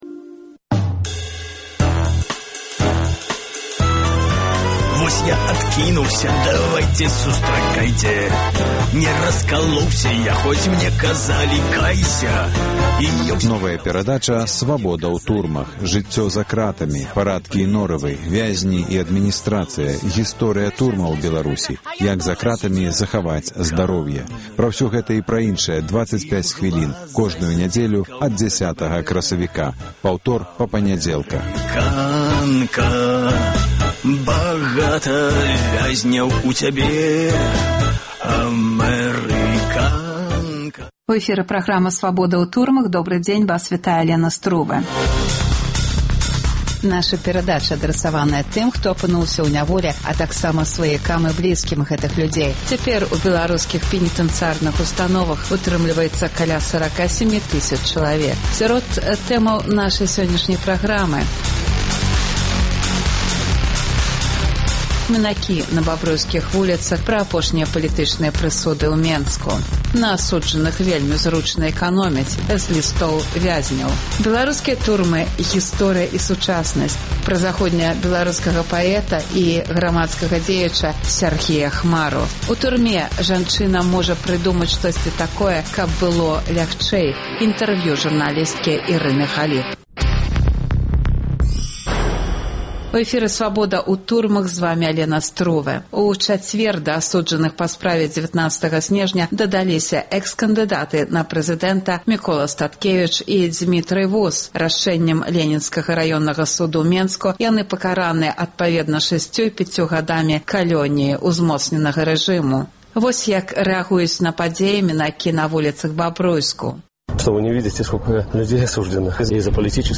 Апытаньне мінакоў на бабруйскіх вуліцах.